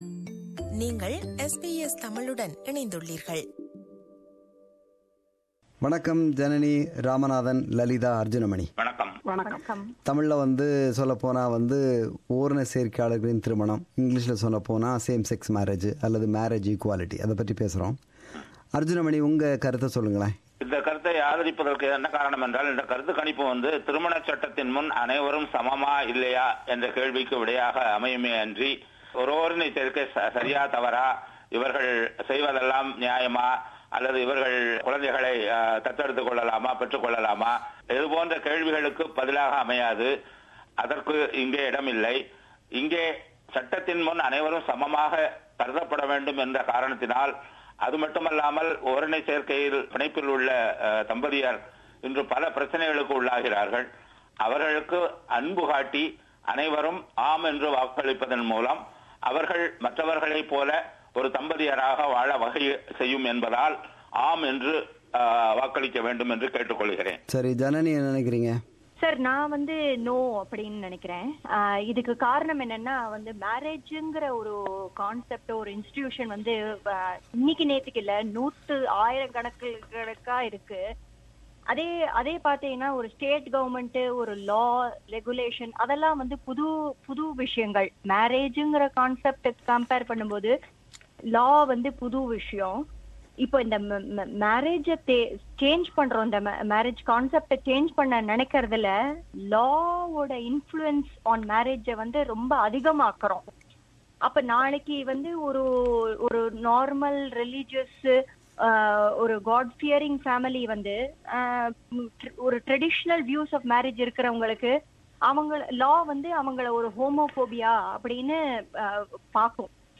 A panel discussion with our panelists